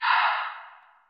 watcher_sigh.ogg